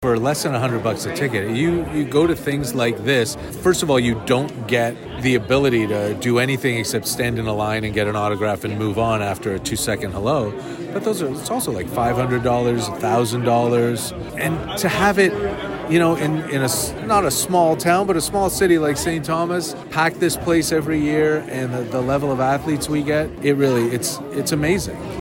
myFM had the chance to speak with some of the head table  guests ahead of the sit-down portion of the evening, who shared why they wanted to get involved in such a special event.